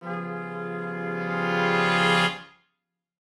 Index of /musicradar/gangster-sting-samples/Chord Hits/Horn Swells
GS_HornSwell-G7b2b5.wav